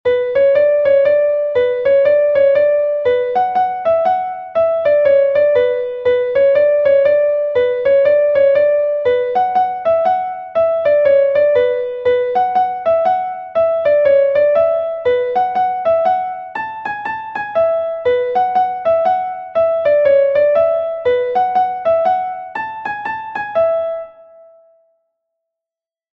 De fil en aiguille II est un Laridé de Bretagne enregistré 1 fois par FMB